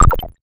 REDD PERC (15).wav